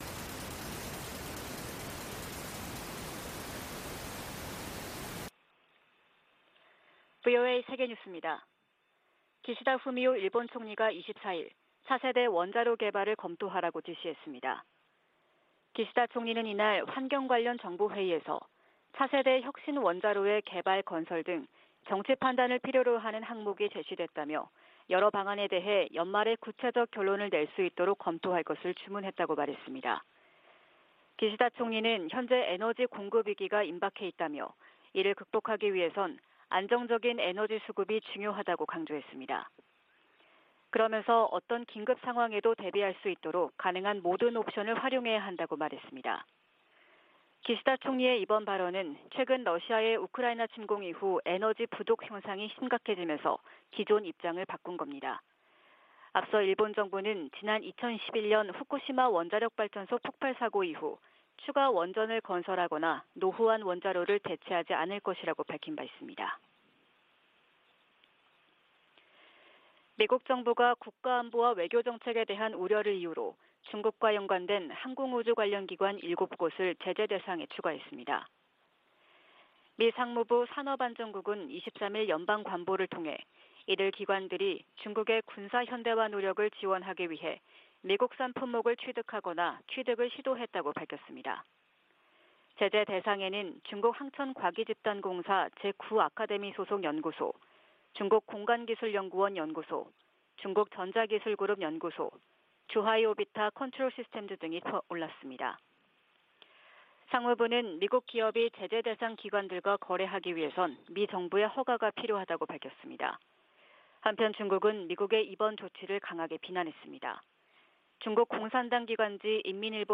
VOA 한국어 '출발 뉴스 쇼', 2022년 8월 25일 방송입니다. 제10차 핵확산금지조약(NPT) 평가회의가 한반도의 완전한 비핵화를 지지하는 내용이 포함된 최종 선언문 초안을 마련했습니다. 에드워드 마키 미 상원의원은 아시아태평양 동맹과 파트너들이 북한의 핵 프로그램 등으로 실질적 위협에 직면하고 있다고 밝혔습니다. 미국 정부가 미국인의 북한 여행 금지조치를 또다시 연장했습니다.